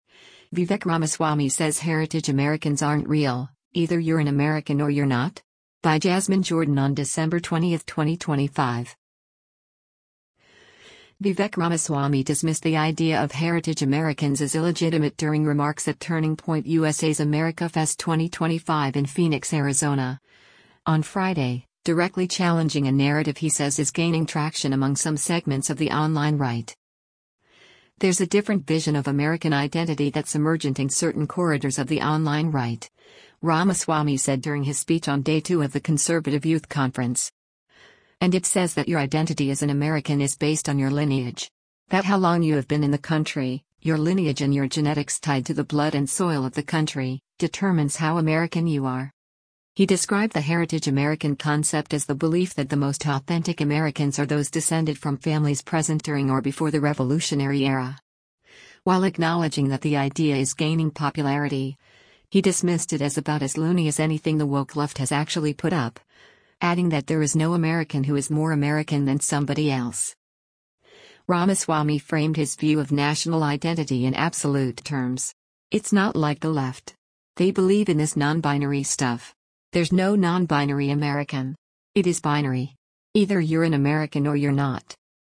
Vivek Ramaswamy dismissed the idea of “Heritage Americans” as illegitimate during remarks at Turning Point USA’s AmericaFest 2025 in Phoenix, Arizona, on Friday, directly challenging a narrative he says is gaining traction among some segments of the “online right.”